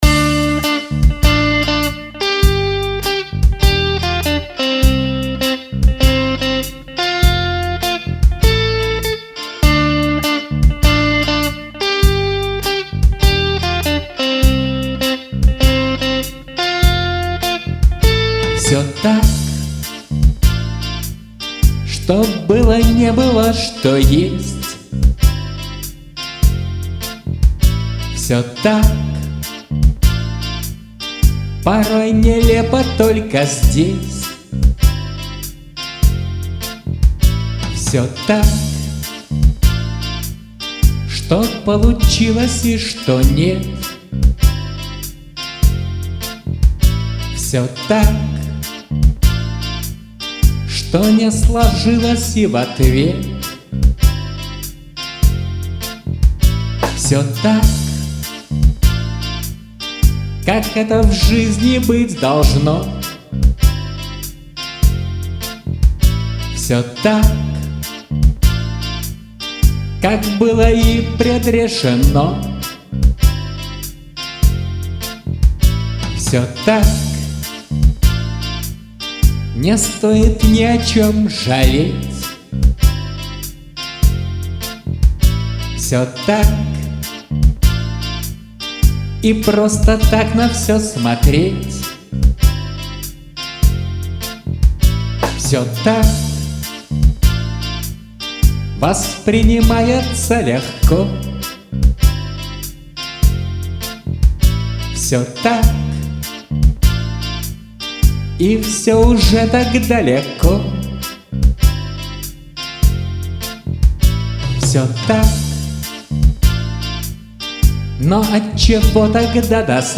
(2018, Рок)